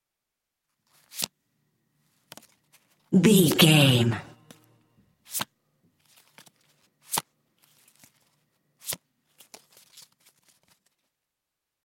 Deck playing cards deal table
Sound Effects
foley